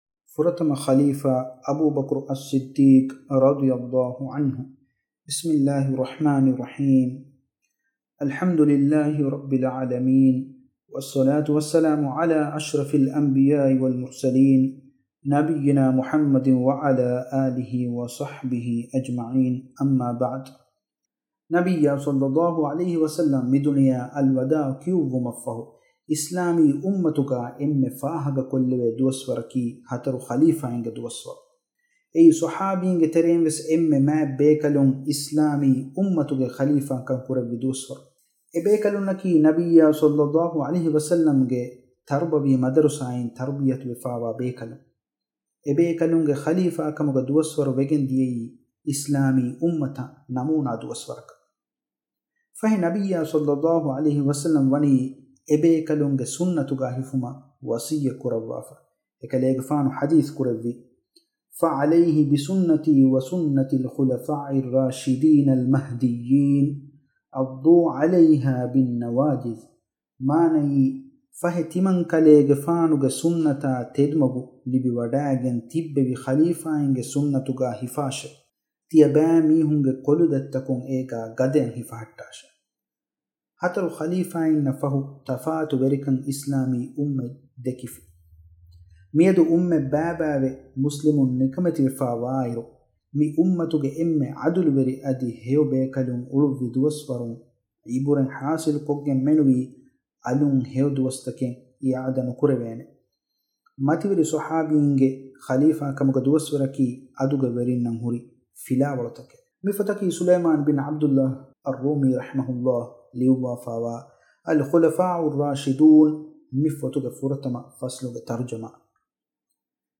އޯޑިއޯ ފޮތް | ފުރަތަމަ ޚަލީފާ: އަބޫބަކުރު އައްޞިއްދީޤު – رضي الله عنه – 1